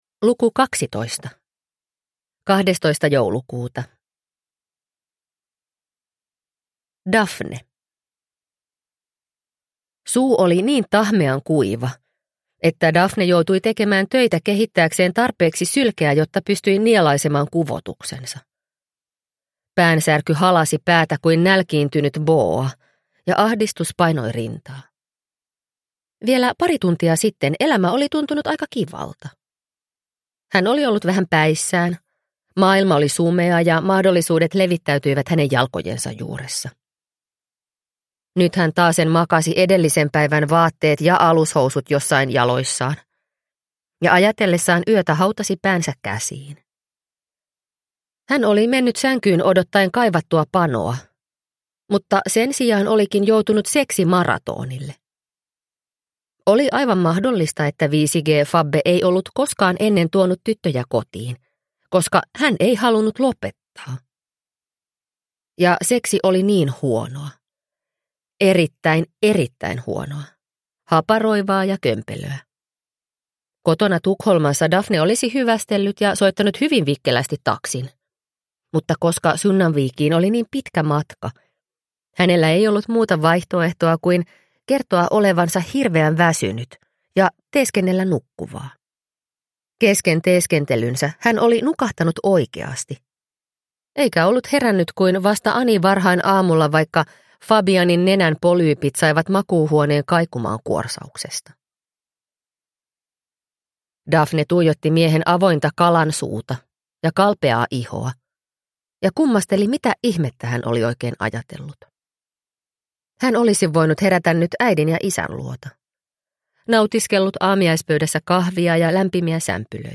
Sankt Annan joulu – Ljudbok – Laddas ner